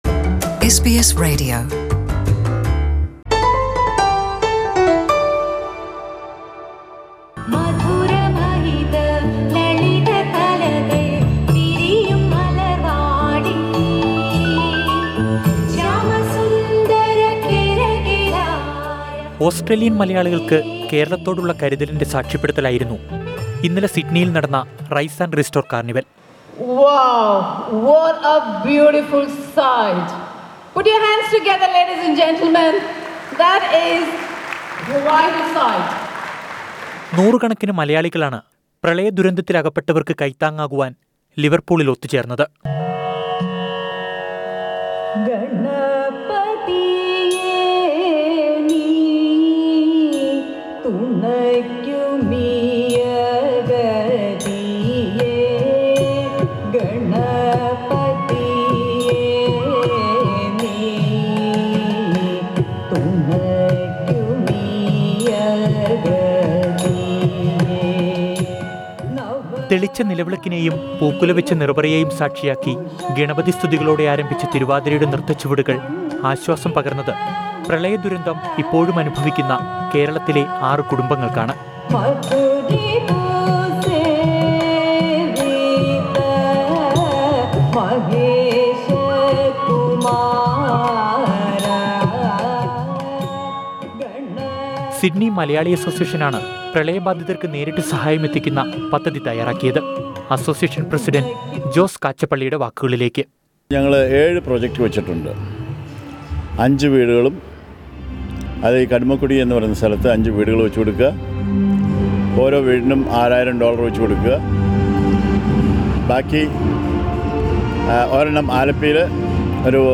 Sydney Malayalees have come forward to help raise fund for six families who lost their houses in Kerala flood. Listen to a report on this.